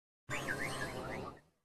Cri de Koraidon dans Pokémon Écarlate et Violet.